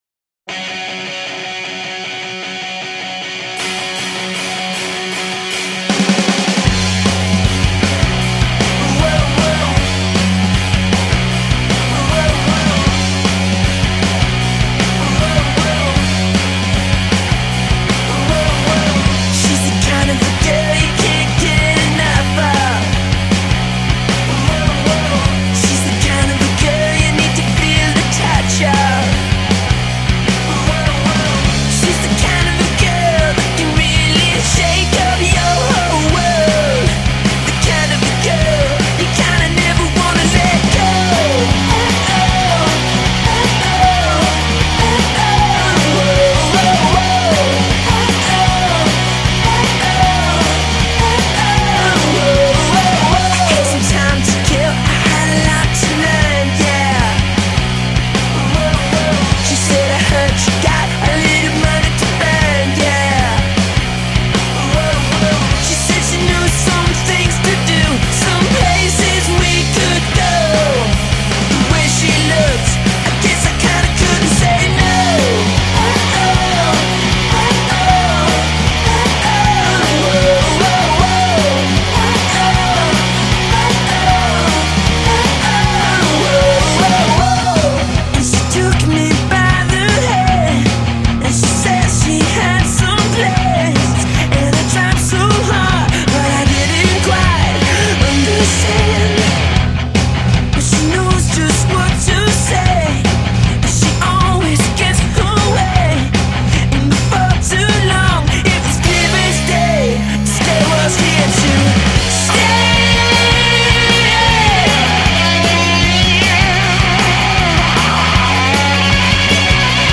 who can churn out sunny pop with brutal efficiency.